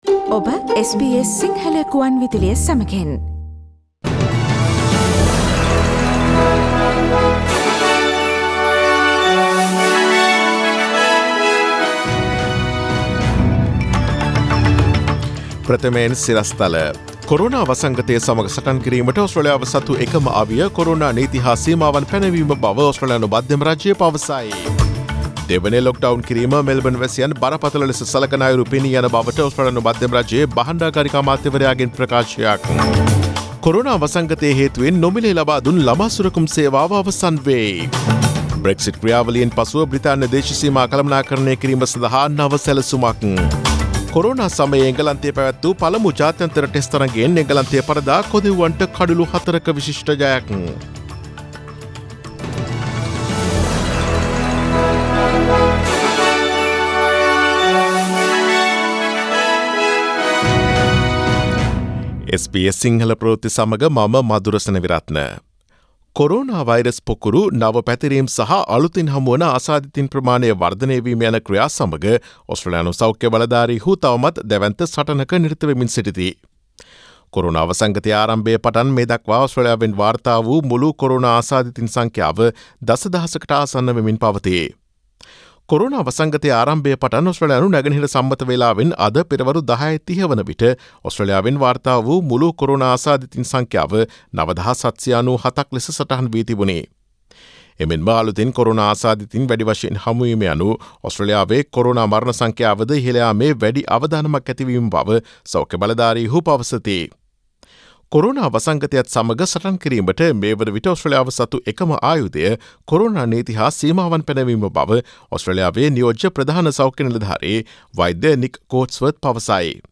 Daily News bulletin of SBS Sinhala Service: Monday 13 July 2020